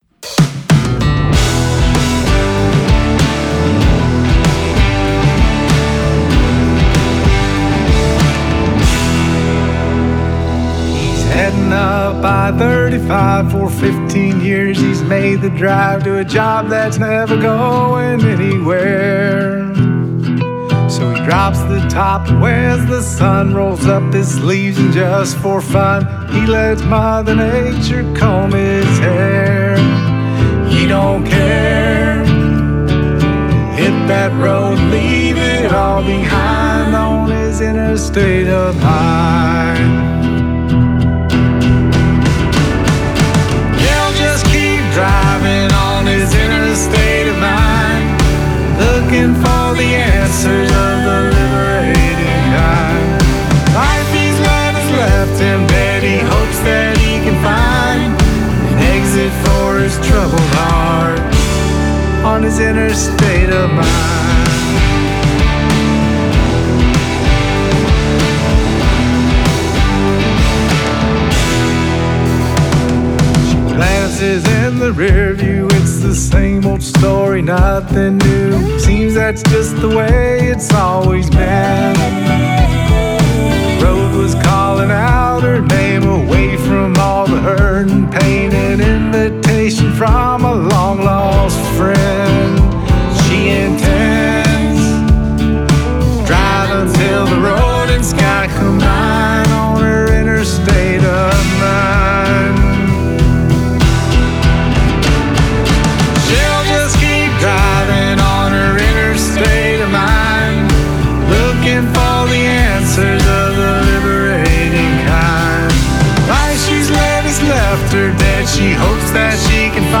gravados em Nashville.
gravados no renomado Castle Recording Studio em Nashville.
entre o rock de raízes e o folk contemporâneo